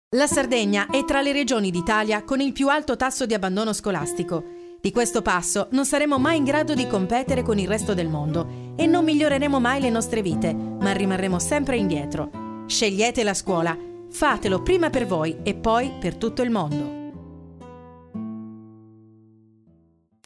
Spot campagna sensibilizzazione "Istruzione e scuola 2025"